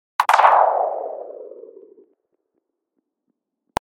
格斗音效
描述：格斗游戏音效，踢和拍击声。
标签： 低音的 拍击 爆炸 击打
声道单声道